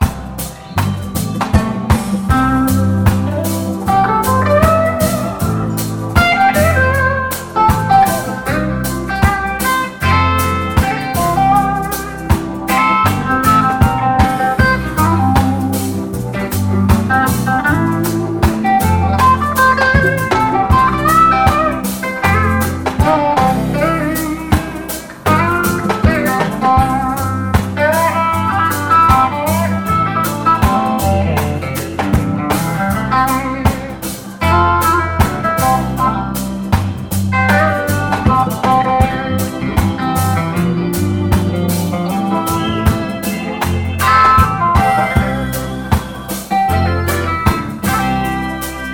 Solo original (accordé) à jouer en boucle :